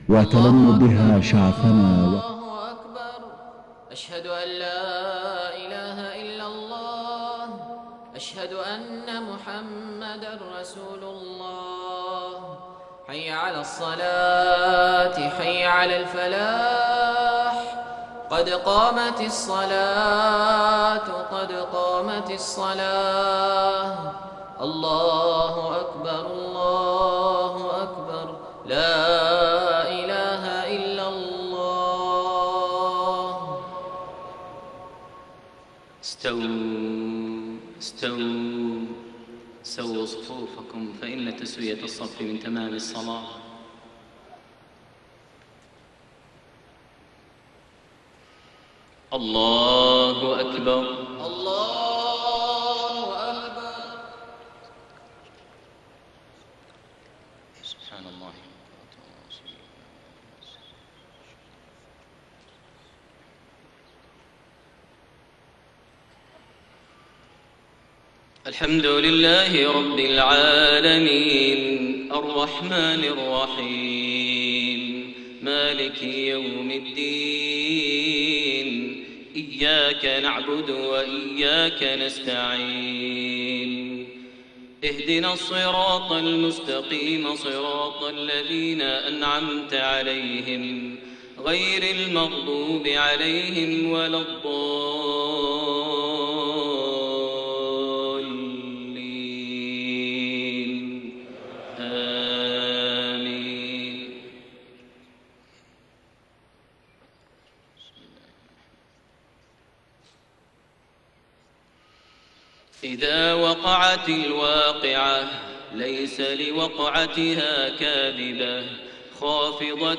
صلاة الفجر 24 رجب 1432هـ | فواتح سورة الواقعة1-56 > 1432 هـ > الفروض - تلاوات ماهر المعيقلي